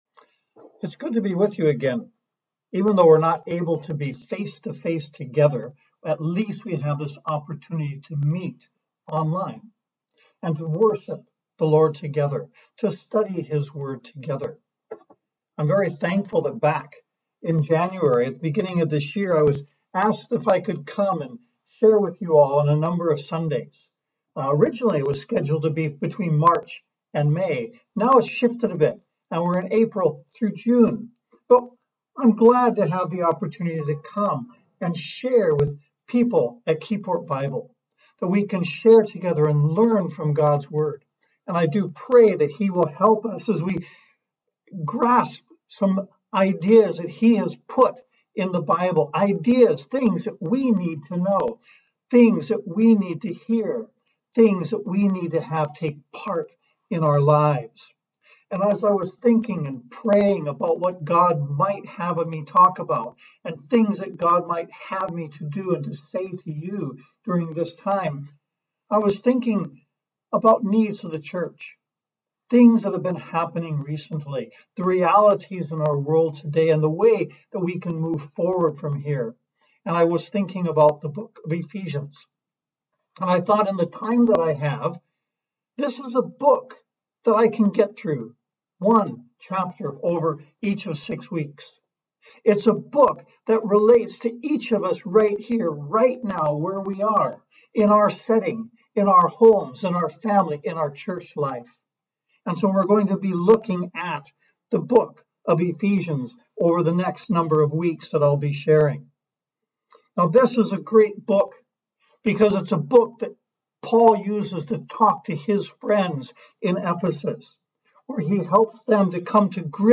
Prelude
Sermon